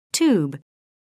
미국 [tjuːb]